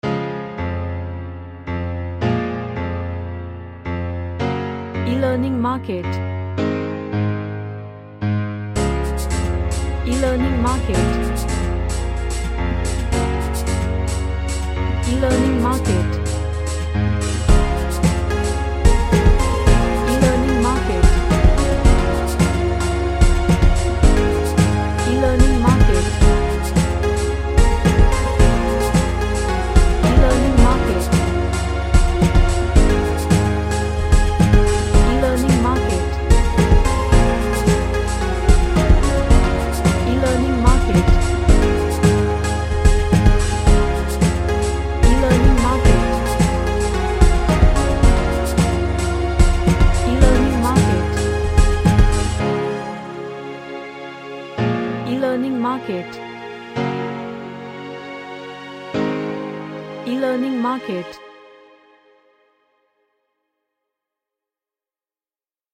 A Happy Xmas Vibe Track With Bells.
HappyUpbeat